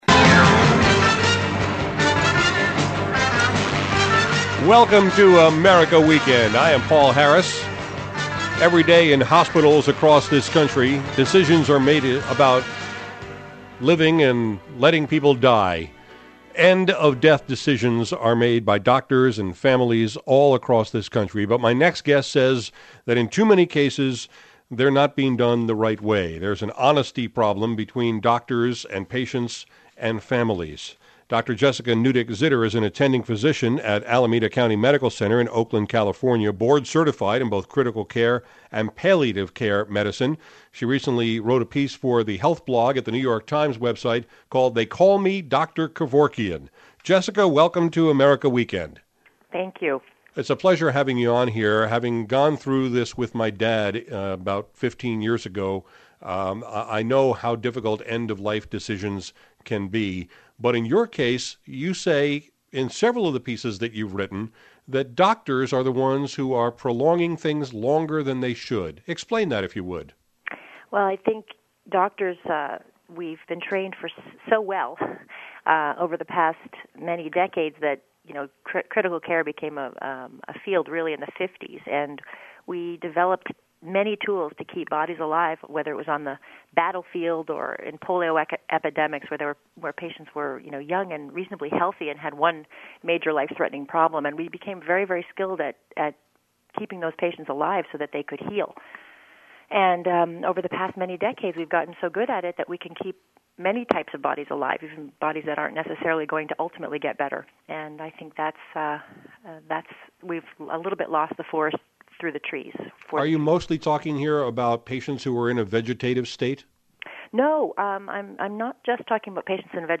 who joined me on America Weekend to talk about her battles with colleagues over letting people die with dignity instead of having their lives prolonged by machinery and medicine.